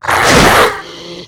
monstermiss.wav